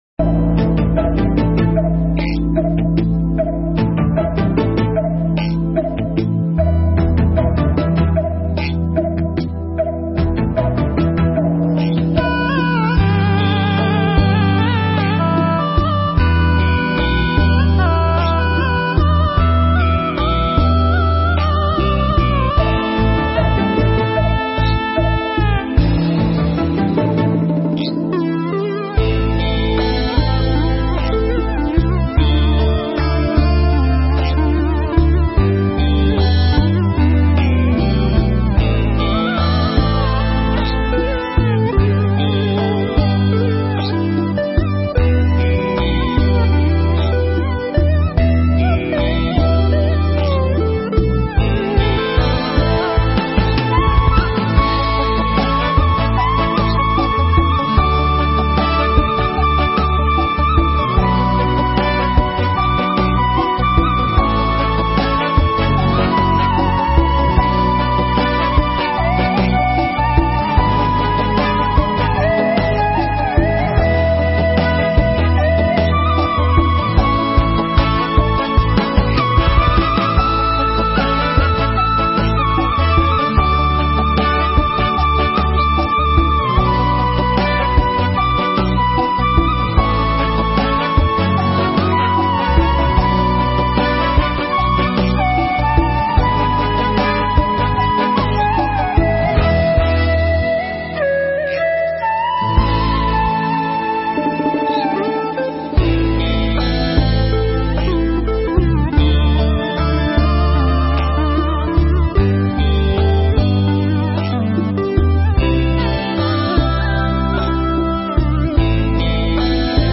Mp3 Pháp Thoại Biết Không Thật Liền Dứt Khổ Đau
giảng trong khóa tu Một Ngày An Lạc 71 tại Tu Viện Tường Vân